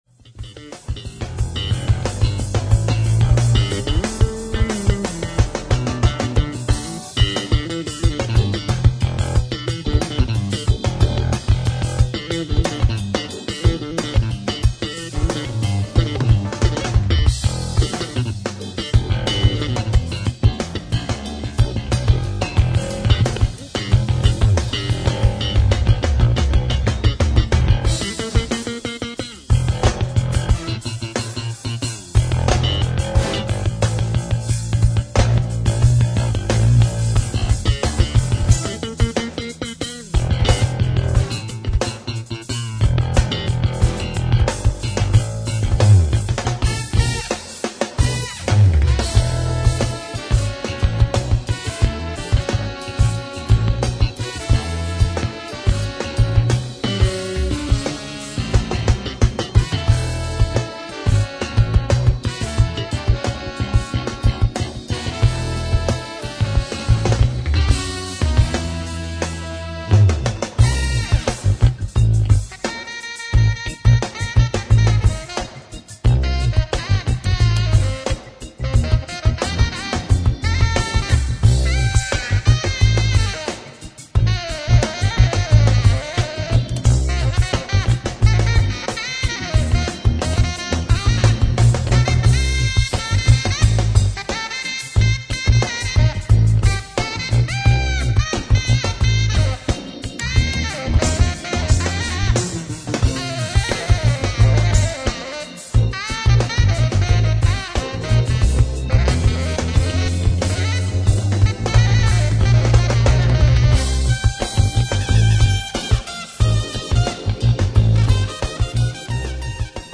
ライブ・アット・バーボン・ストリート・ミュージック・クラブ、サンパウロ、ブラジル 11/07/2001
超Ａ級の再放送音源を収録！！
※試聴用に実際より音質を落としています。